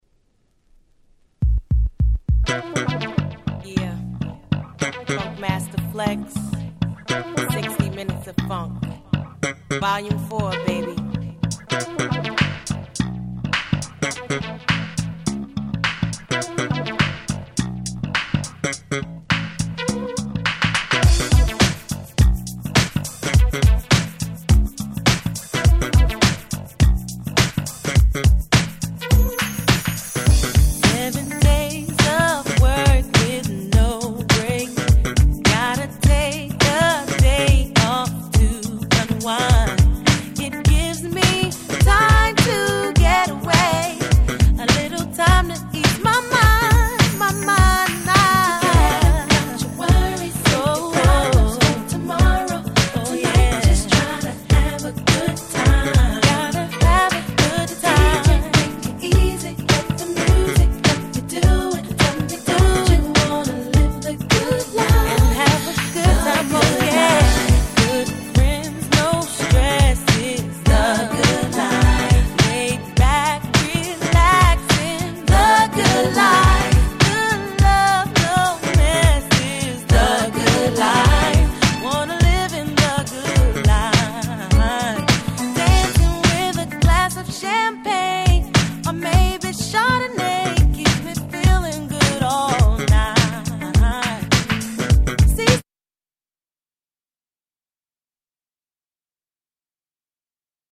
00' Smash Hit R&B♩